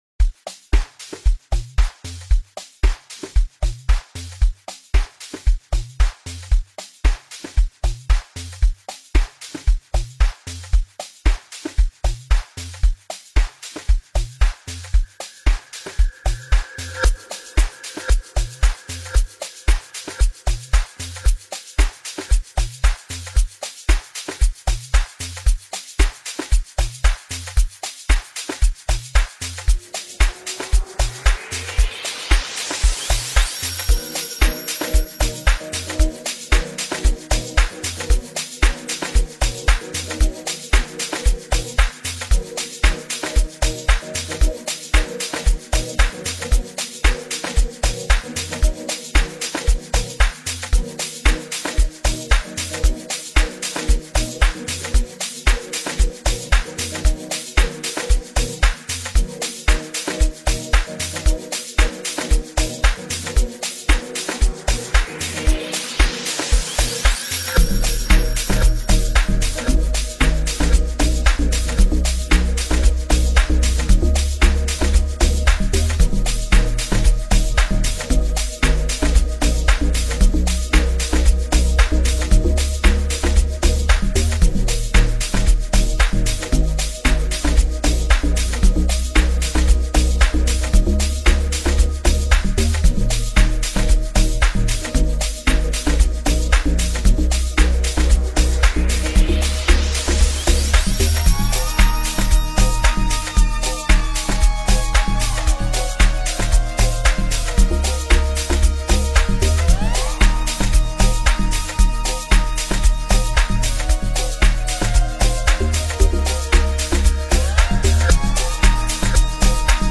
piano duo
bright melodies and epic synths